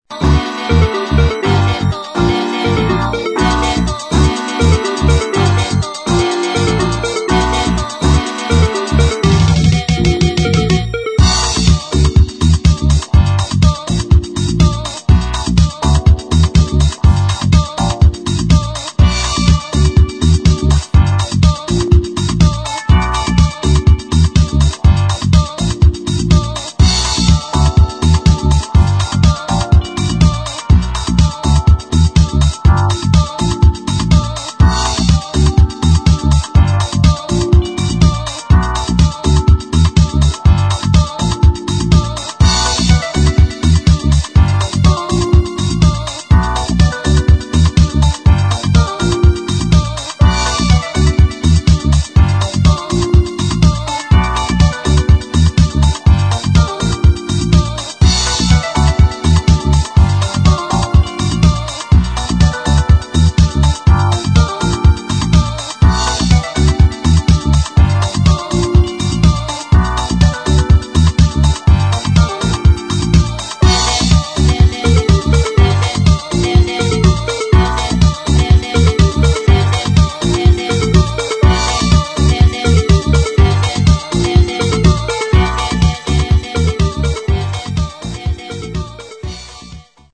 [ DEEP HOUSE | TECH HOUSE | TECHNO ]